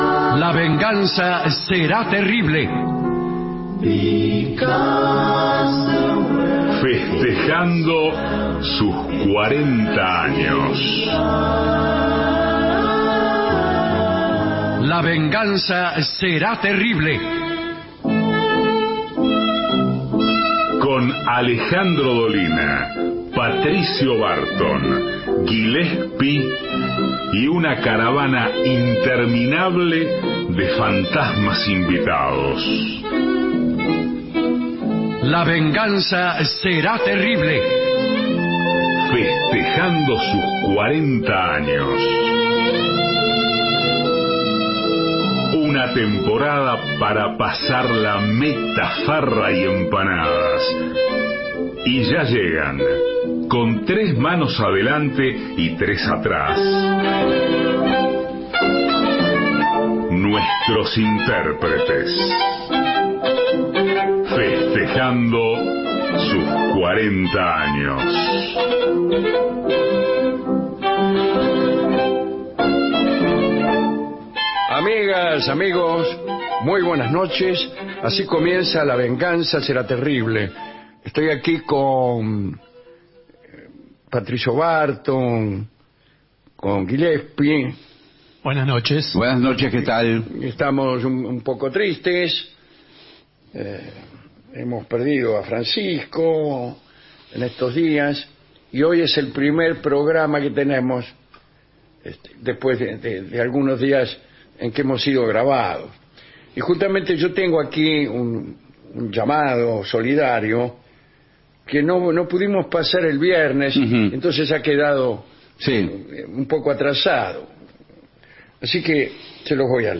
todo el año festejando los 40 años Estudios AM 750 Alejandro Dolina